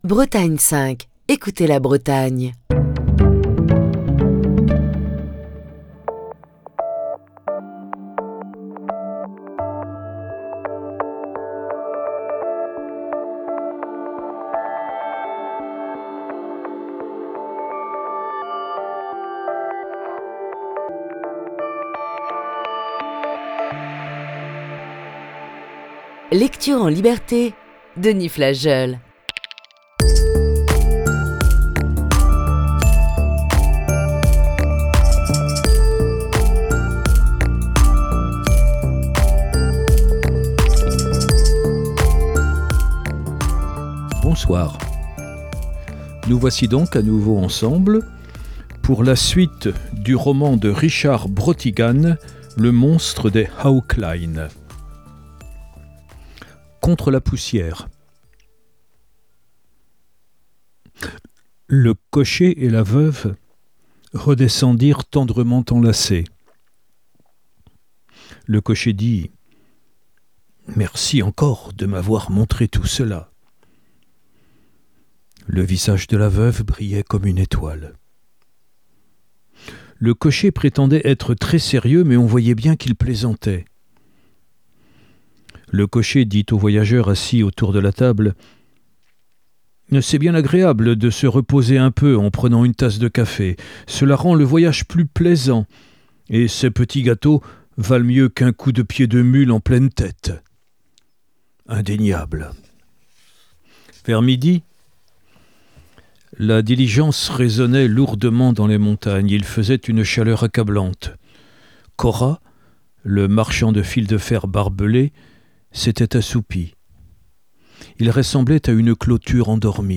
Émission du 24 mai 2022.